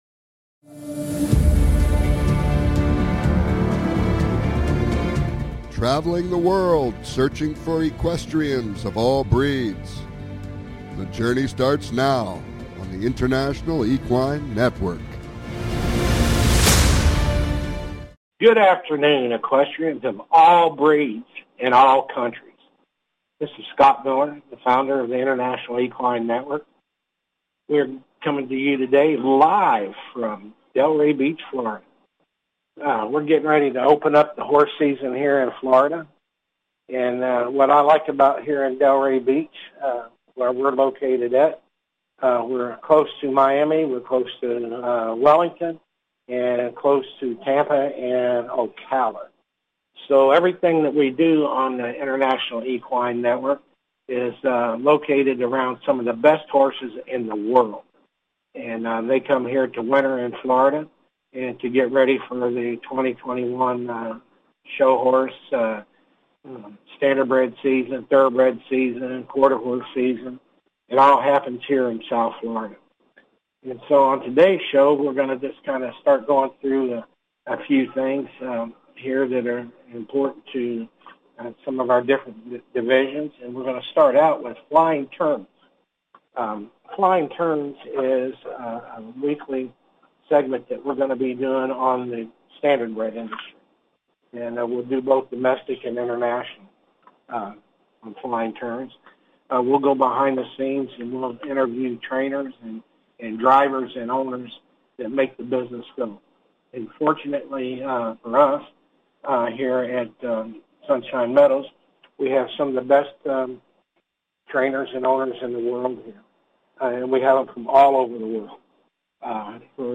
Calls-ins are encouraged!